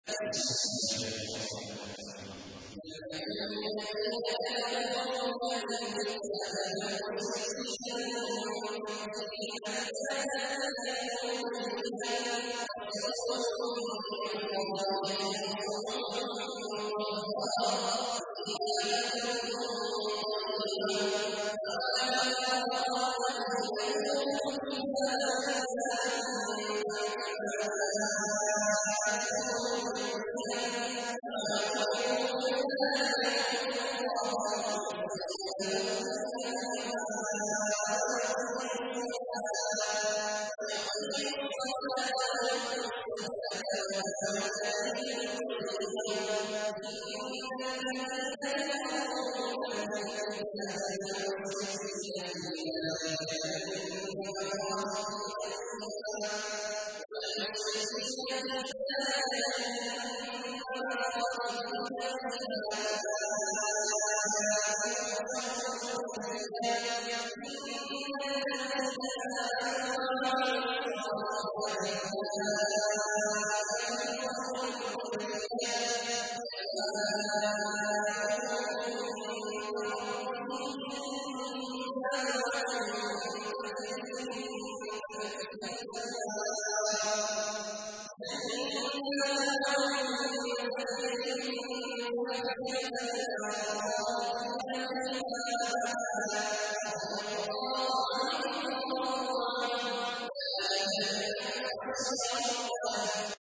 تحميل : 98. سورة البينة / القارئ عبد الله عواد الجهني / القرآن الكريم / موقع يا حسين